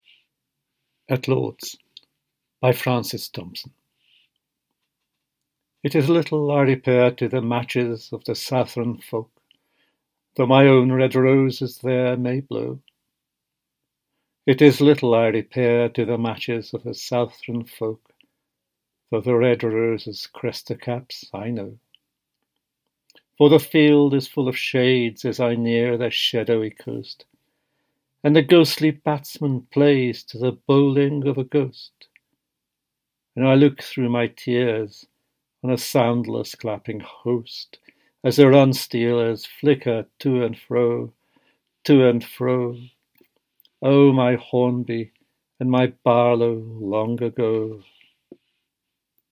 At Lord’s by Francis Thompson read by George Szirtes
At-Lords-by-Francis-Thompson-read-by-George-Szirtes.mp3